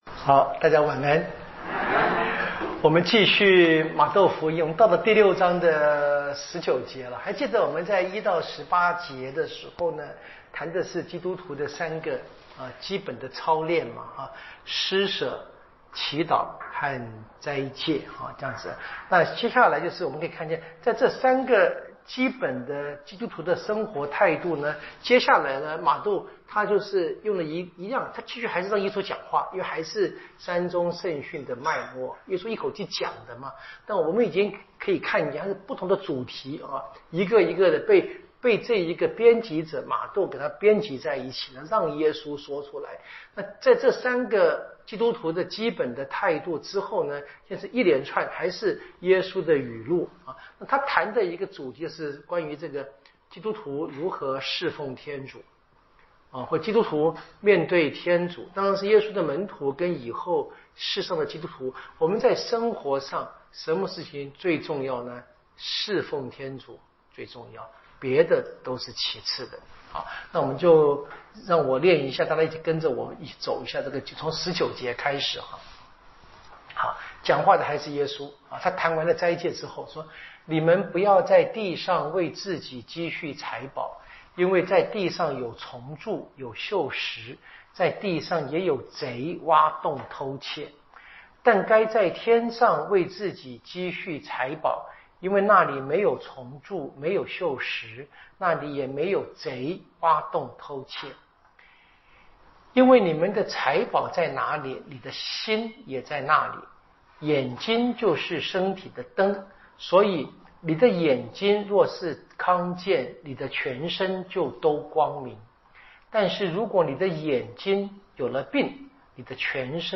圣经讲座